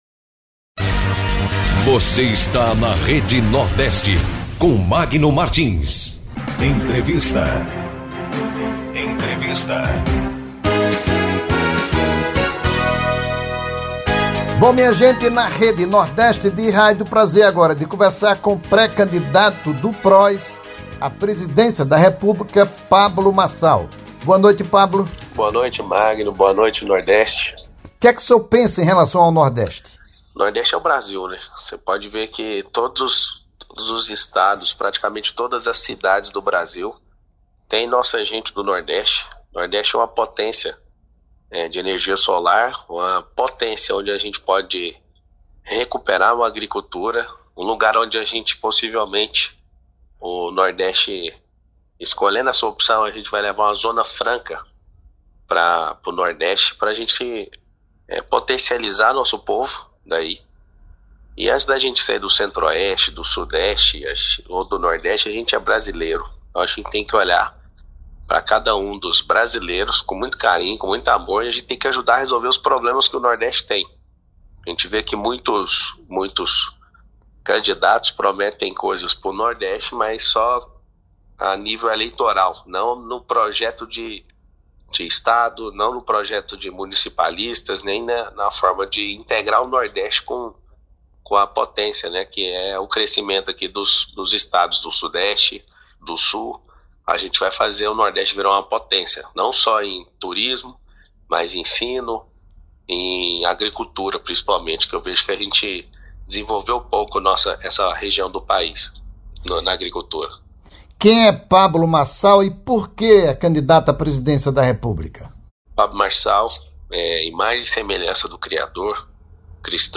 entrevista-com-Pablo-Marcal.mp3